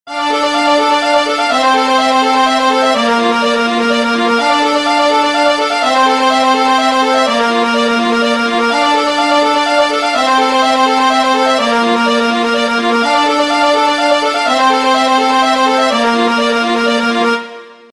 без слов
аккордеон , ost